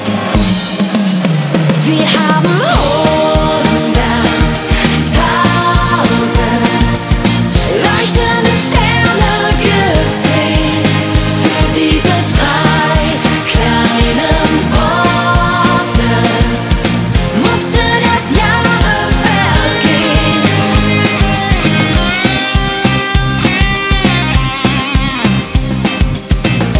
Gattung: Moderner Einzeltitel
B Besetzung: Blasorchester PDF
Schlager vom Feinsten.